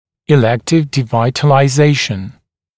[ɪ’lektɪv dɪˌvaɪtəlaɪ’zeɪʃn] [и’лэктив диˌвайтэлай’зэйшн] избирательное умерщвление (пульпы зуба)